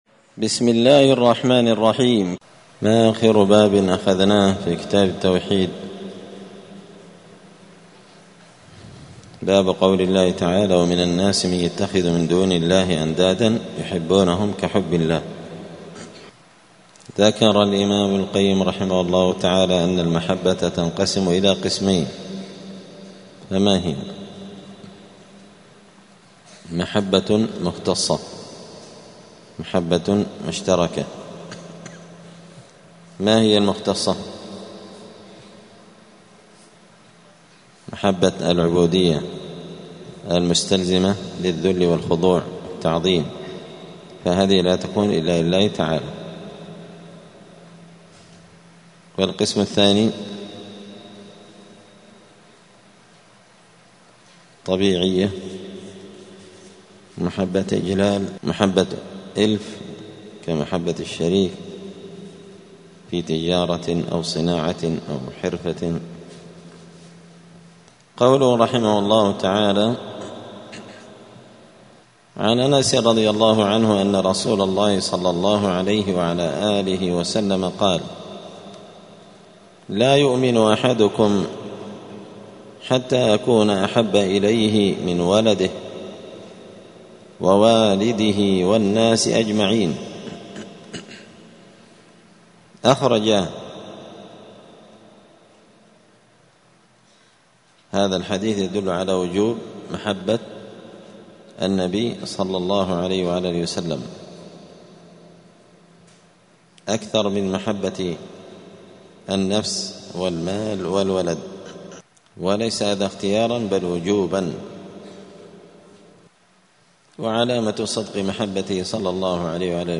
دار الحديث السلفية بمسجد الفرقان قشن المهرة اليمن
*الدرس السابع والثمانون (87) {تابع لباب قول الله تعالى ومن الناس من يتخذ من دون الله أندادا}*